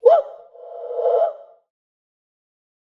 Vox (Woah).wav